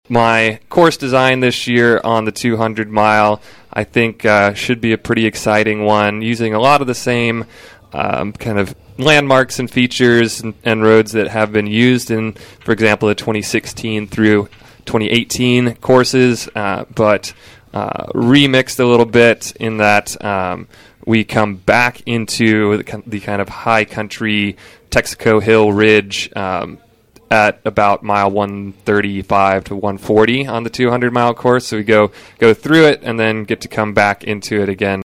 With Unbound Gravel fast approaching, organizers detail course information, volunteer needs on KVOE Morning Show | KVOE